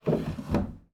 drawer_close.wav